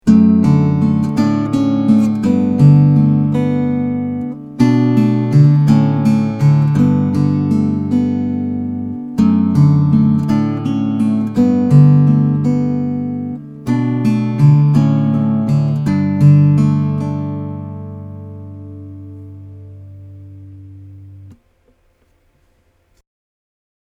シェルター なし シェルター あり 極力マイクとアコギは同じ位置関係で録音したつもりですが、100％同じではありません。
よって、低音の出方や音量は多少の誤差がありますのでご了承ください。 音の滲み方や乱反射による反響感などを聞いていただければ幸いです。